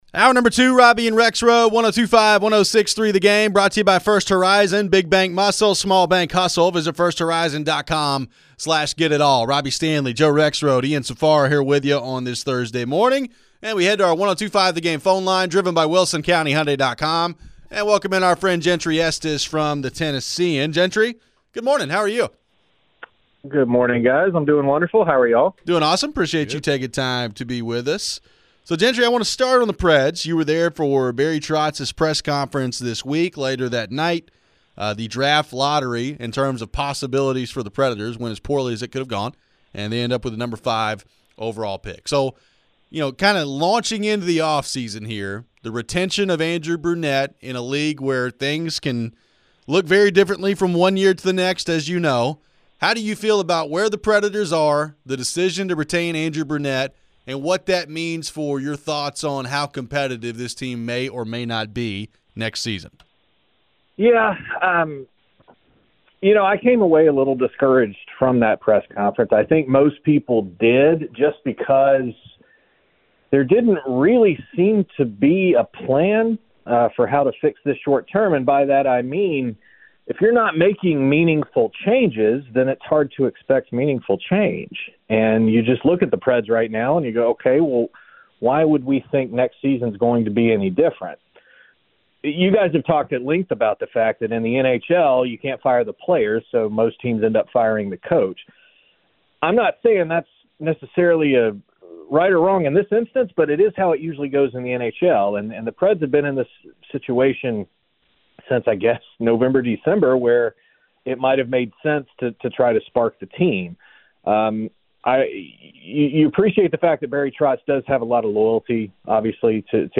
We get back to your phones. What do we think about this story from ESPN that the SEC could potentially increase the conference schedule to nine games?